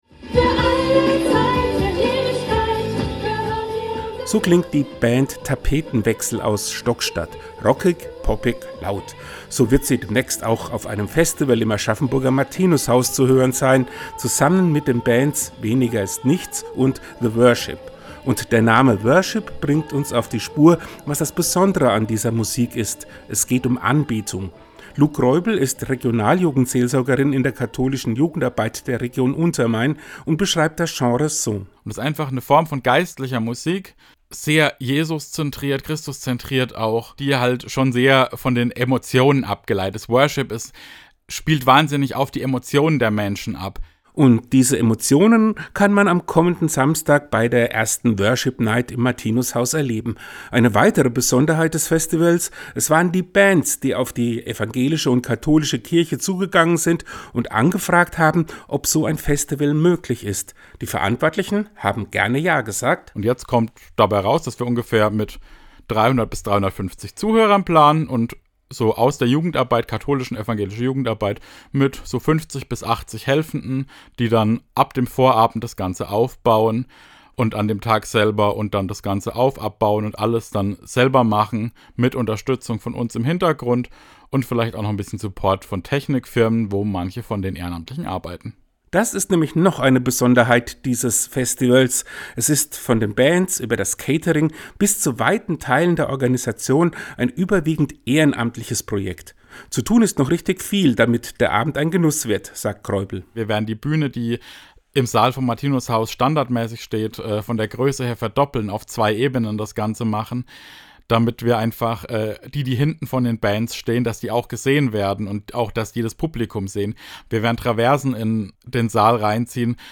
Den Radiopodcast gibt es unten als Download!